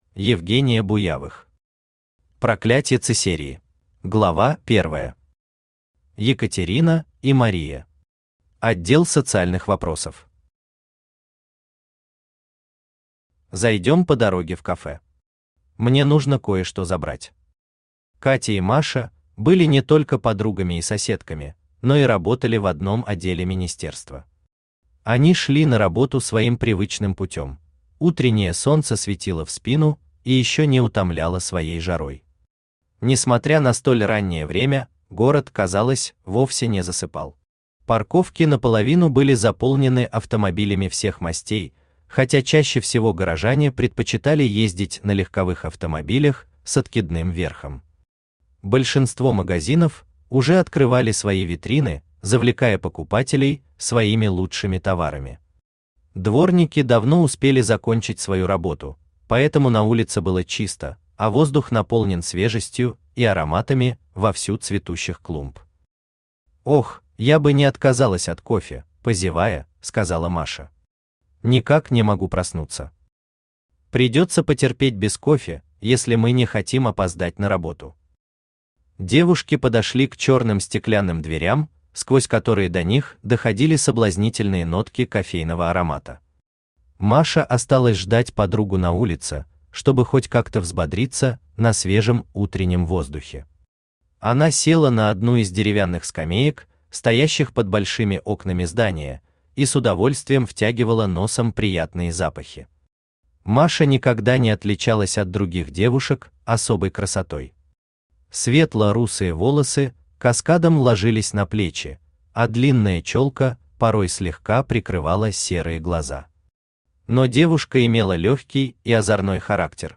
Аудиокнига Проклятье Циссерии | Библиотека аудиокниг
Aудиокнига Проклятье Циссерии Автор Евгения Буявых Читает аудиокнигу Авточтец ЛитРес.